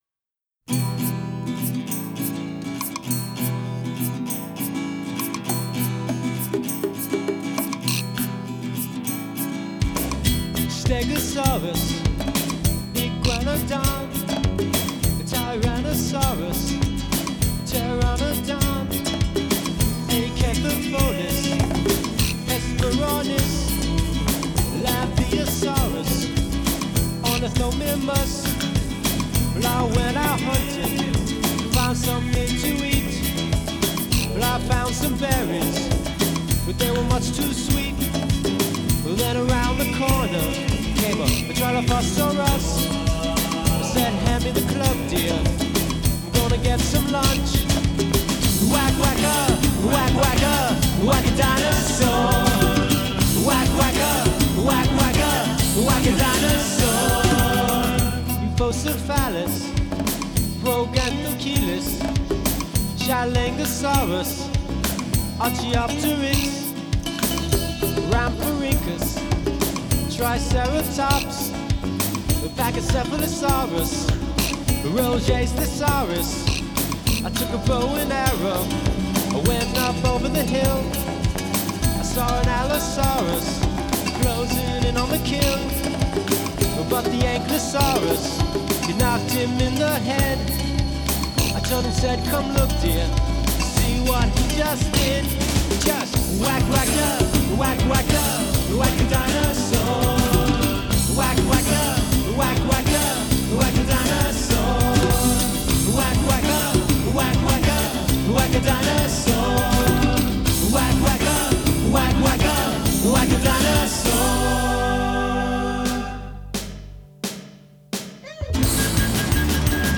a psychedelic jaunt released in 1995.
power/psych-pop band